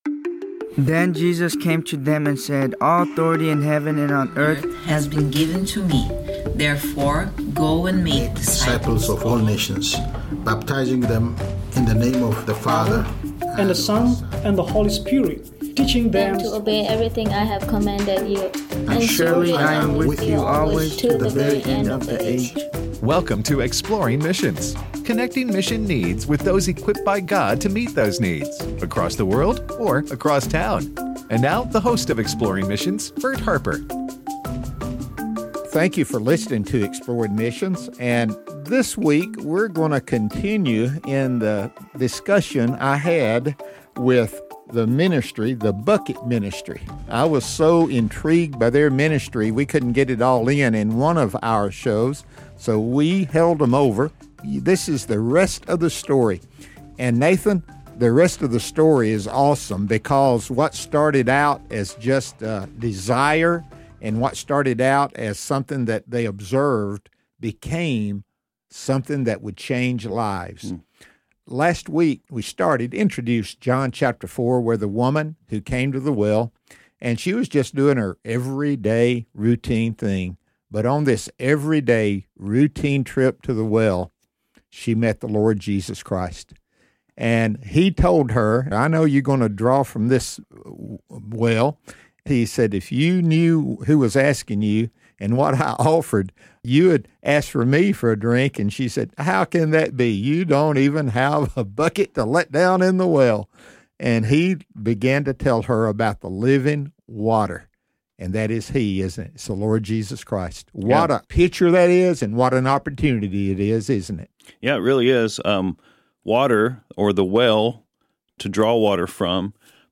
The Bucket Ministry: A Conversation